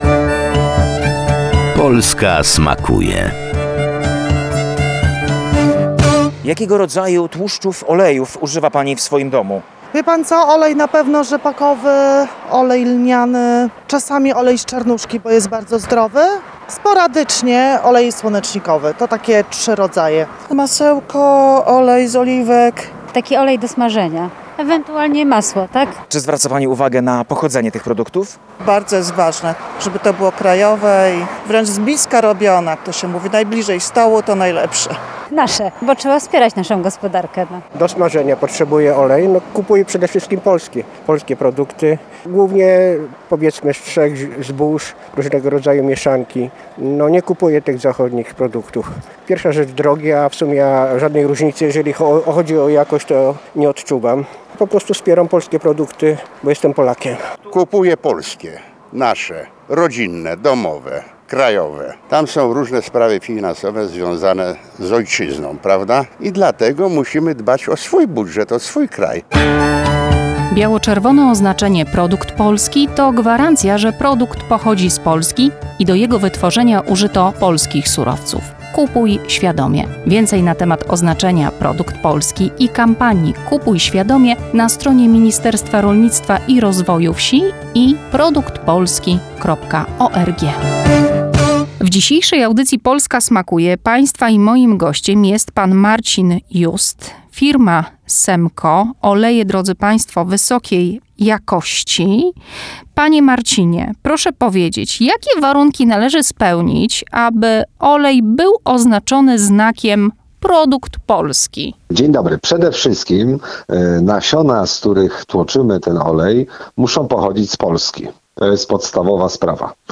Uczestnicy sondy przeprowadzonej na ulicach Warszawy podkreślają, że najchętniej w swoich domach używają oleju rzepakowego oraz lnianego.